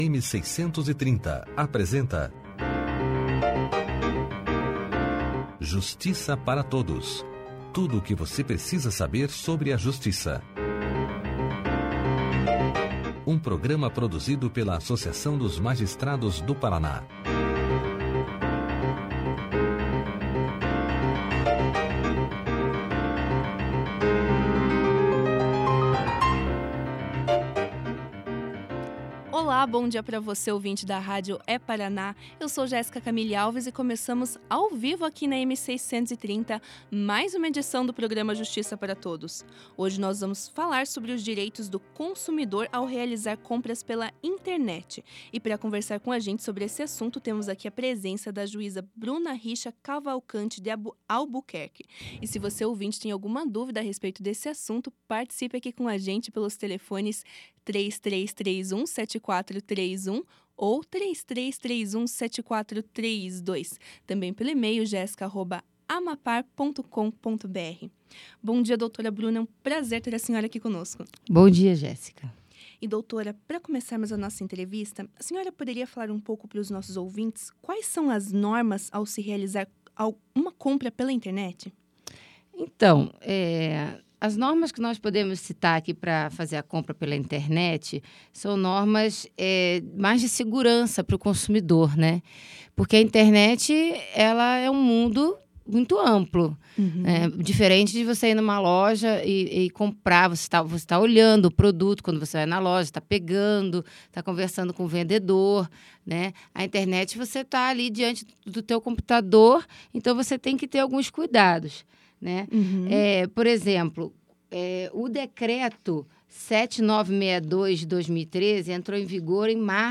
No programa Justiça Para Todos dessa quarta-feira (19), a juíza Bruna Cavalcanti de Albuquerque Zandomeneco esclareceu as principais dúvidas dos ouvintes sobre as compras na internet. Temas como reconhecer os sites confiáveis às compras e, como o consumidor deve proceder ao comprar um produto com defeito foram abordados durante a entrevista.